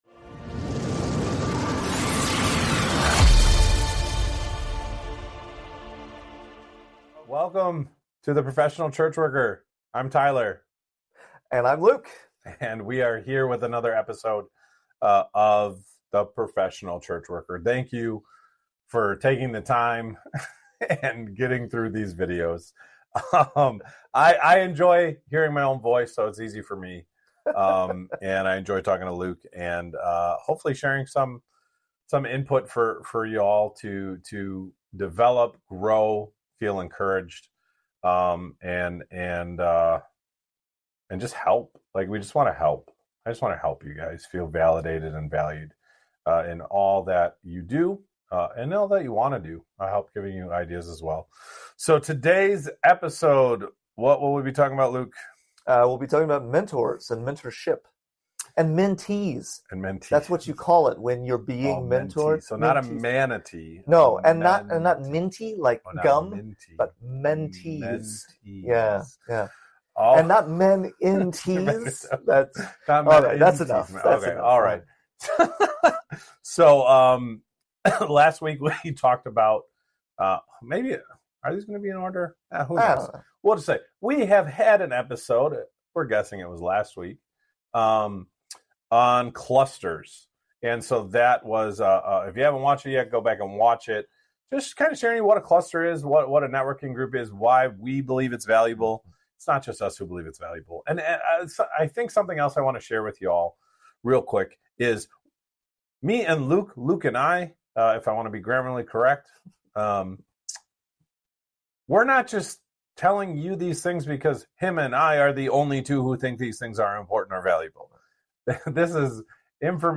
They explore what defines a mentor, the various forms mentorship can take, and the value it brings to personal and professional growth. The conversation emphasizes the need for accountability, guidance, and support from mentors, as well as the responsibility of experienced church workers to mentor others. The hosts share personal experiences and insights on navigating mentorship relationships, concluding with a call to action for listeners to seek out mentors and be mentors themselves.